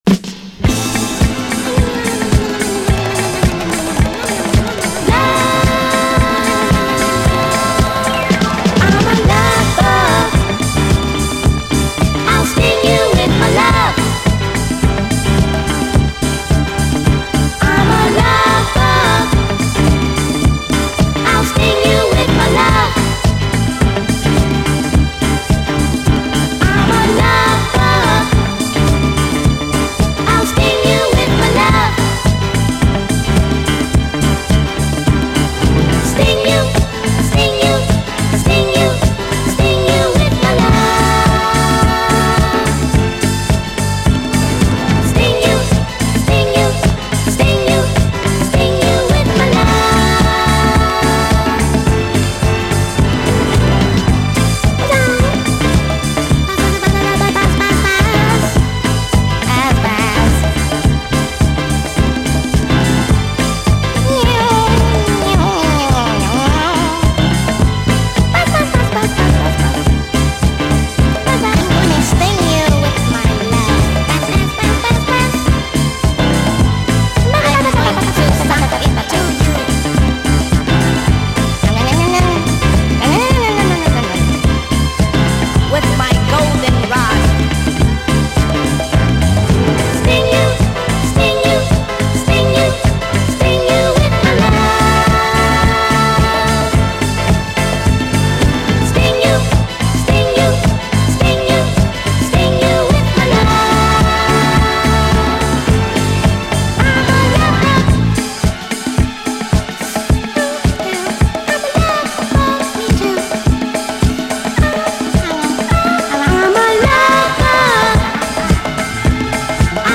SOUL, 70's～ SOUL, DISCO, 7INCH
NYアンダーグラウンド・ディスコ〜ガラージ・クラシック！
ムシ声風コーラスがストレンジ、しかし華麗なストリングスが舞うグレイト・ディスコ！